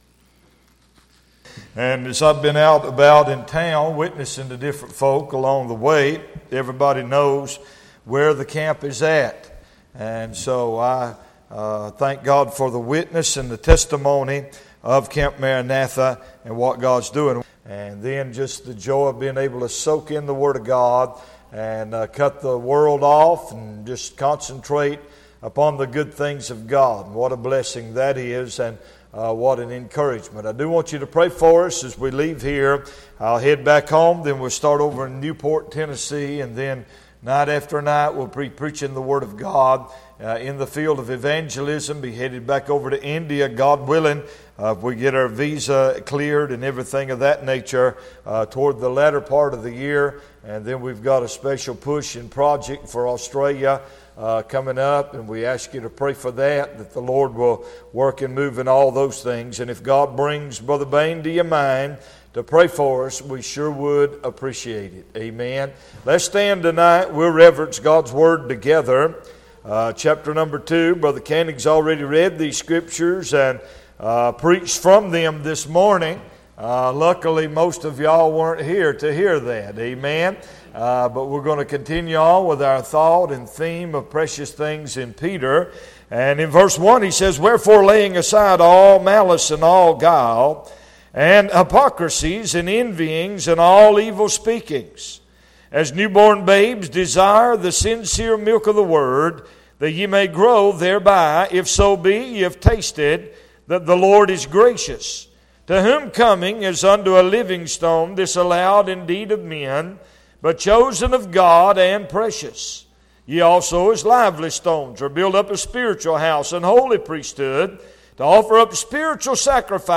Series: 2016 August Conference
Session: Evening Session